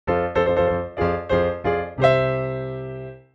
congratulations.wav